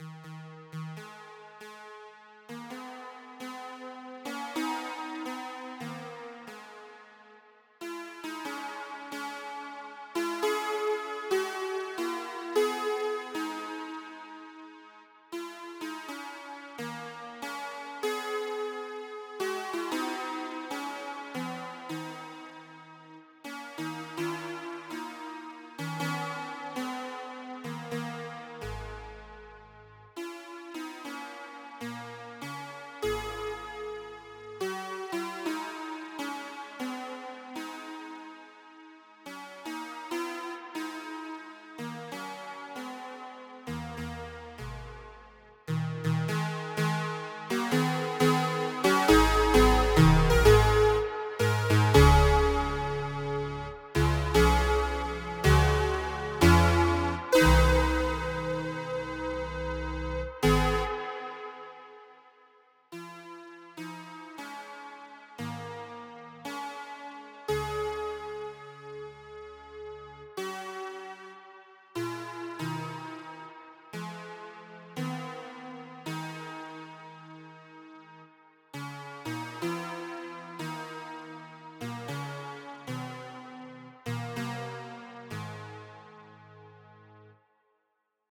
Necro Creed (solo piano)
Also using some odd time signatures here and there.